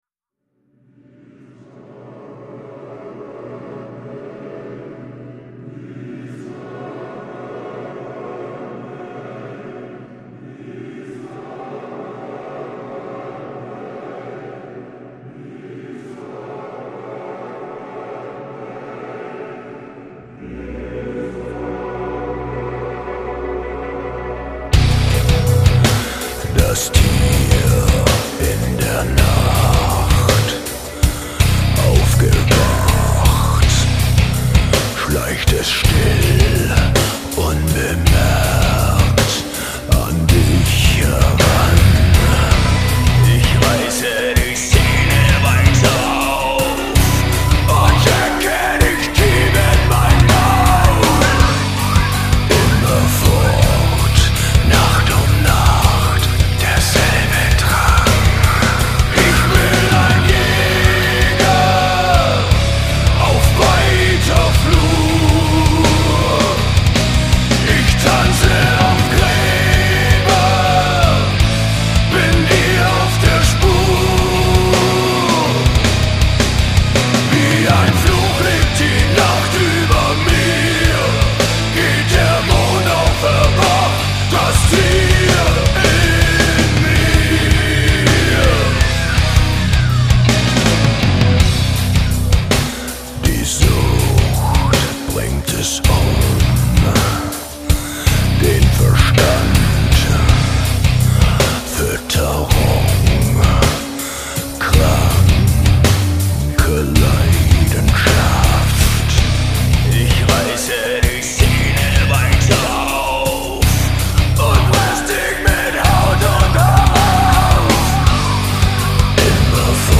Жанр: Industrial, Alternative